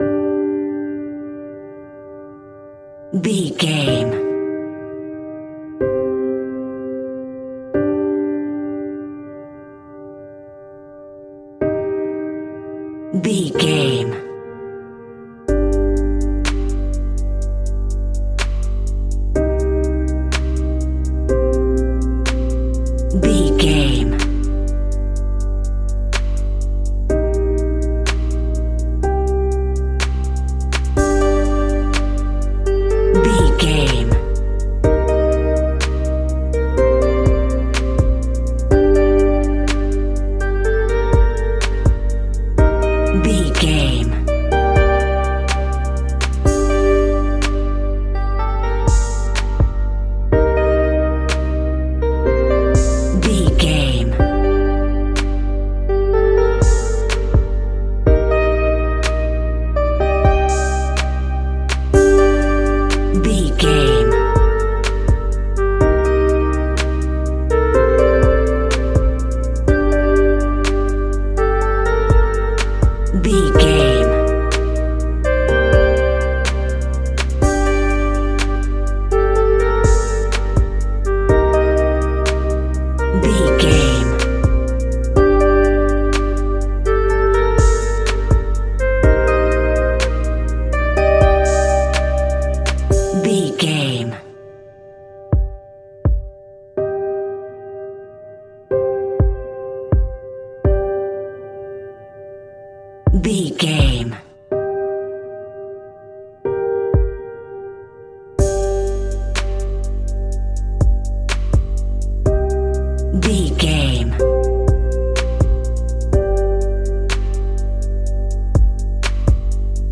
Aeolian/Minor
Slow
relaxed
tranquil
synthesiser
drum machine